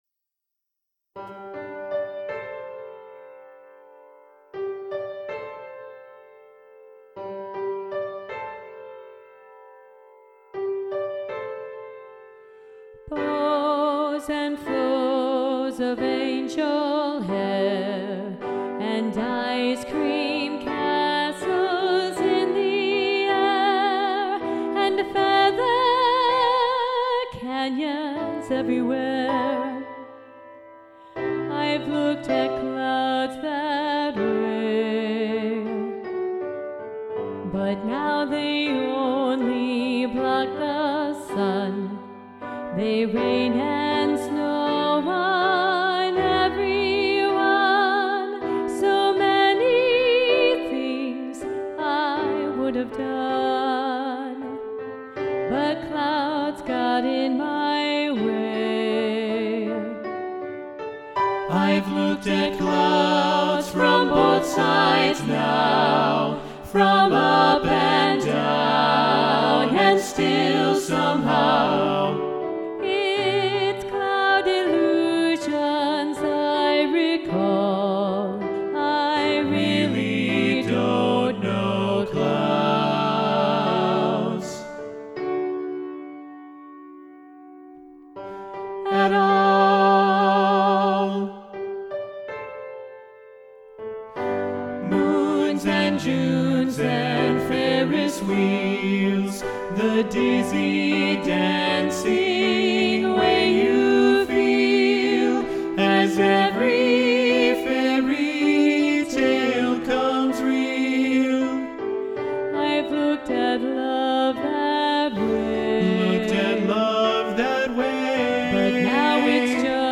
Soprano 1 Muted
Both-Sides-Now-SATB-Soprano-1-Muted-arr.-Roger-Emerson.mp3